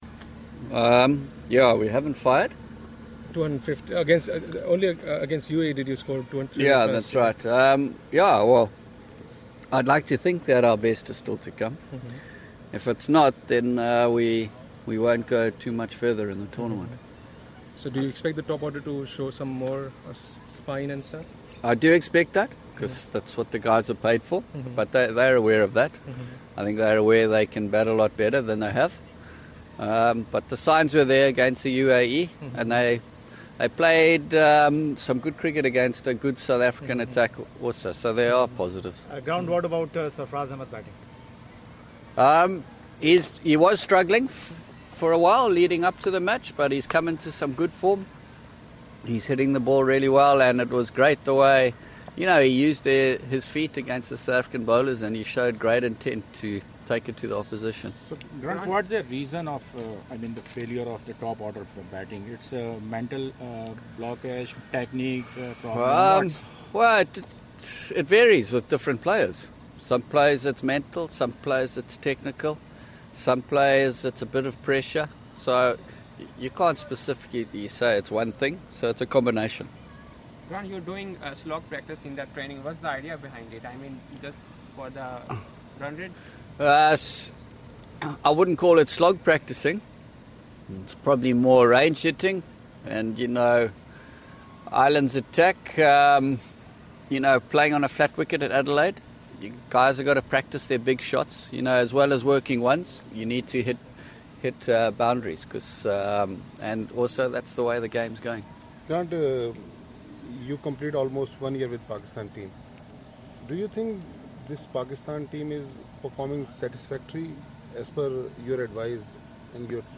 Media Talk: Grant Flower at Adelaide, Oval (Audio)